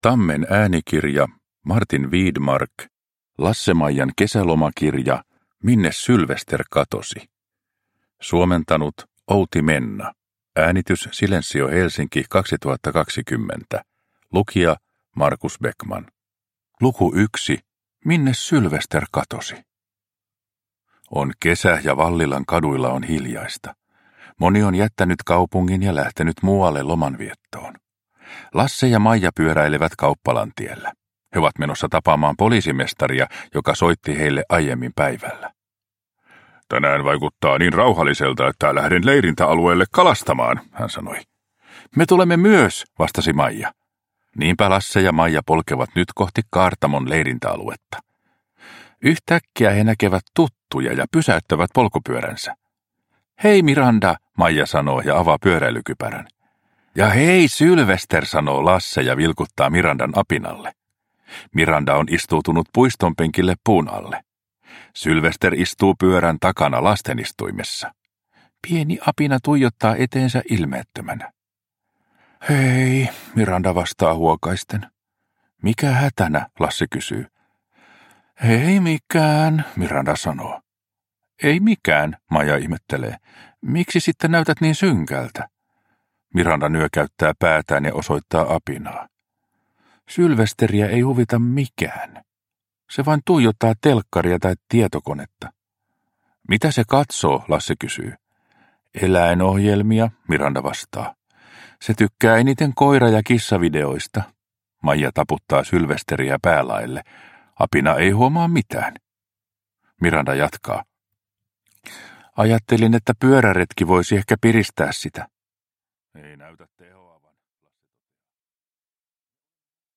Lasse-Maijan lomakesäkirja 2 – Ljudbok